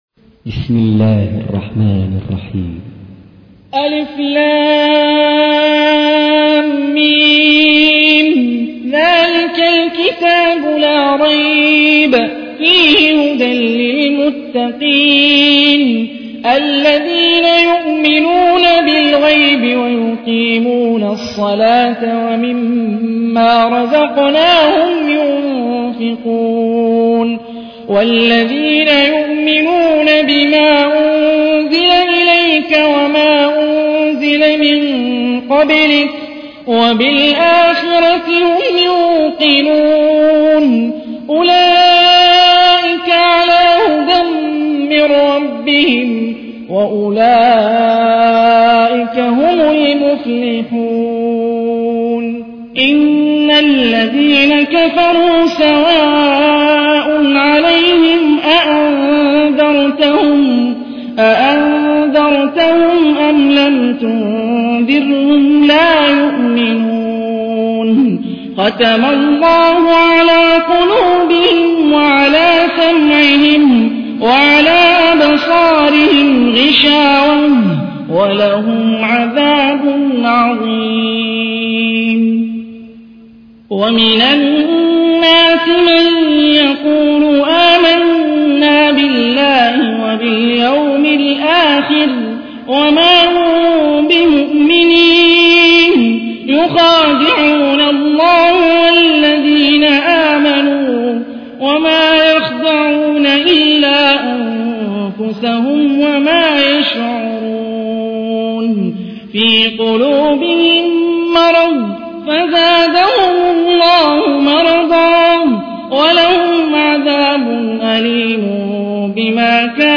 تحميل : 2. سورة البقرة / القارئ هاني الرفاعي / القرآن الكريم / موقع يا حسين